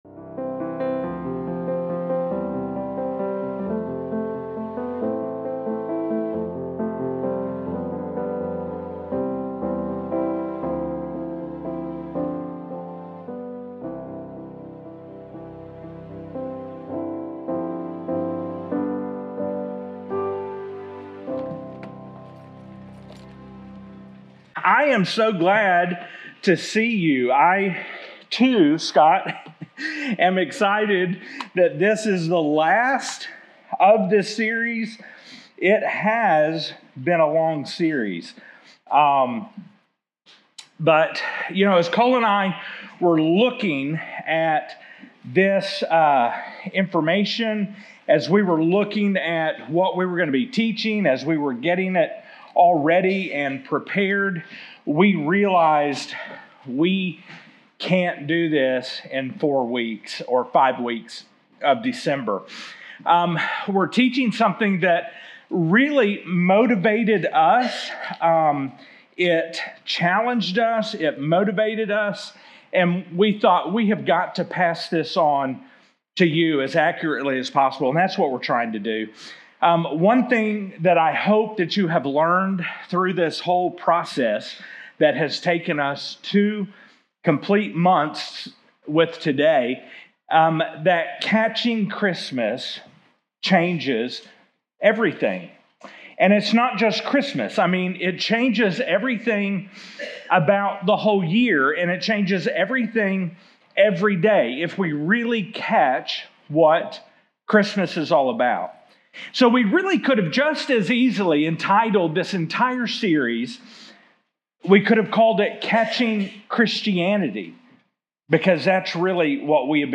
Download Download From this series Current Sermon 9 of 9 Catching Christmas|Catching Christmas Catching Christmas Right On Time...